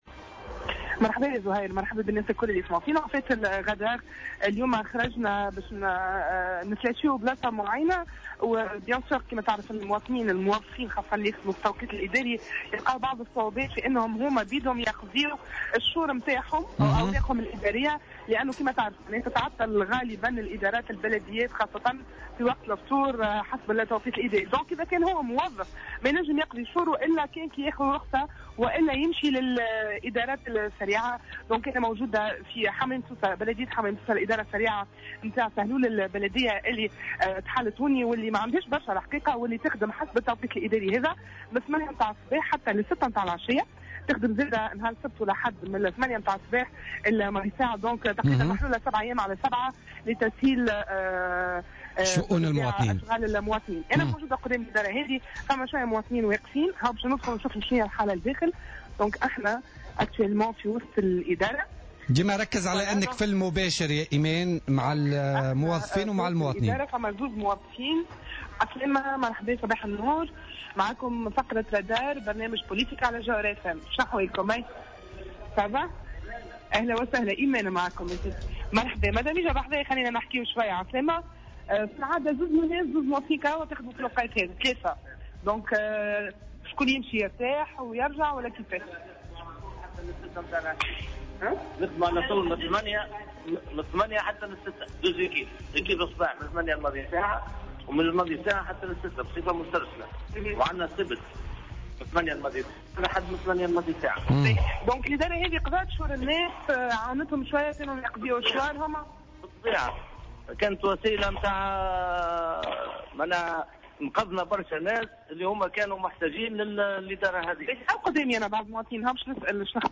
حلّ فريق فقرة "الرادار" اليوم الاربعاء بمقر الإدارة السريعة التابعة لبلدية حمام سوسة.